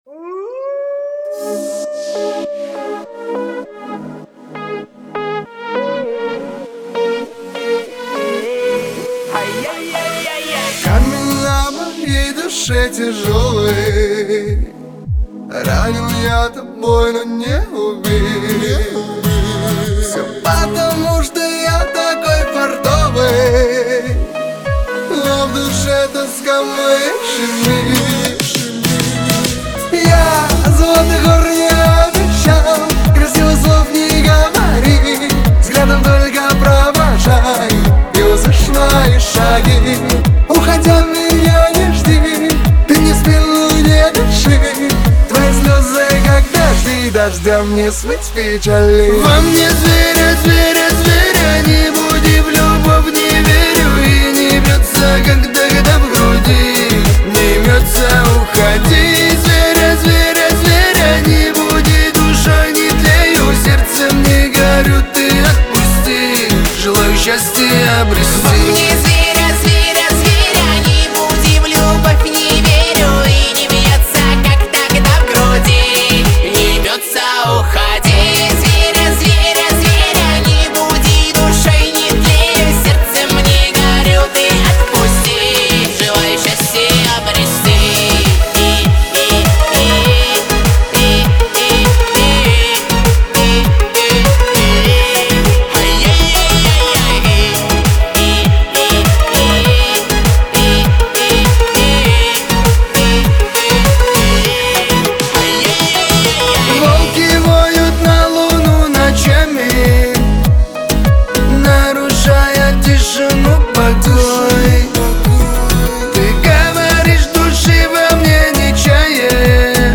Качество: 320 kbps, stereo
Поп музыка, Кавказская музыка